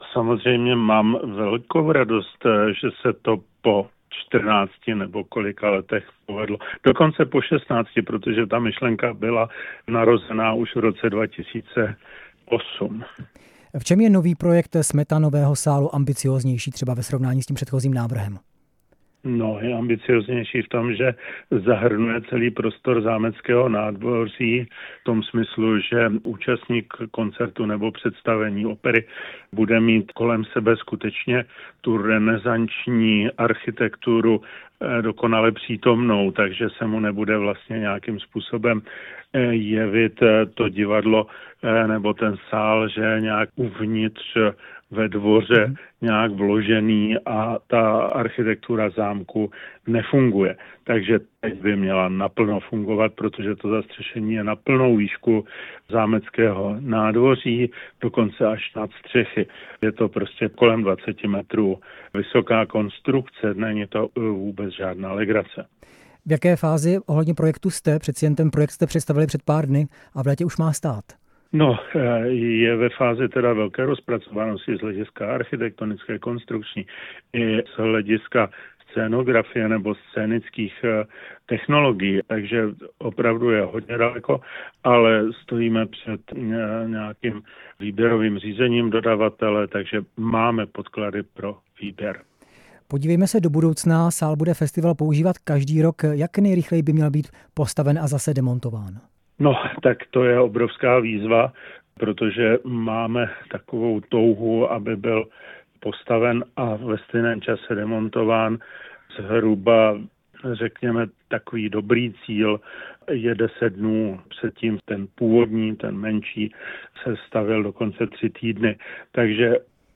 Navrhl ho přední český architekt Josef Pleskot, který byl také hostem ve vysílání Radia Prostor.